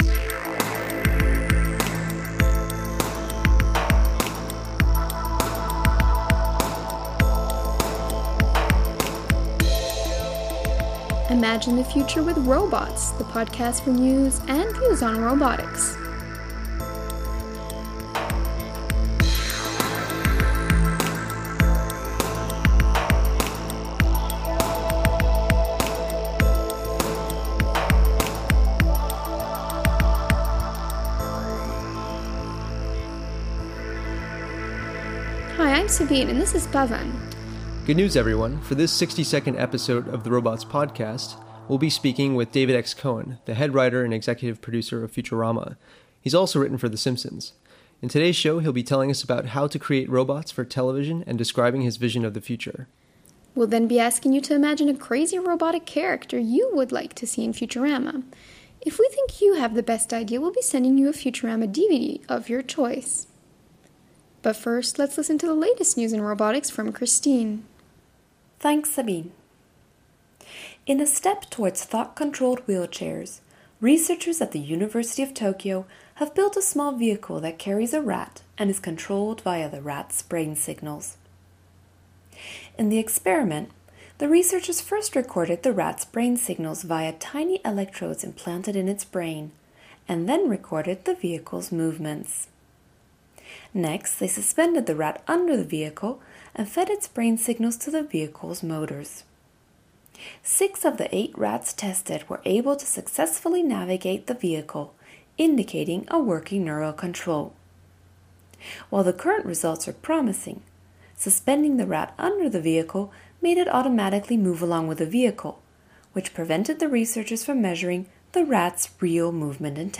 In today’s episode we speak with David X. Cohen, the head writer and executive producer of Futurama!
The ROBOTS Podcast brings you the latest news and views in robotics through its bi-weekly interviews with leaders in the field.